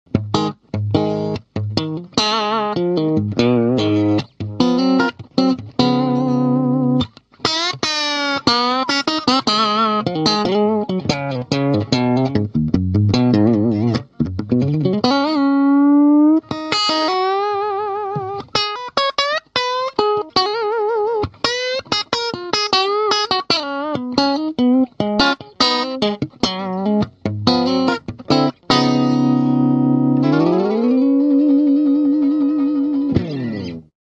Compressor with Fast Response and Hiss Noise Reduction
It also makes Stable Compressor effect or Powerful Squeezing Sound, according to the Input range.
Hiss Reduction Knob works Automatically on the High Gain and reduces the noise naturally to make clean sound.
Demo with Single Pickup 1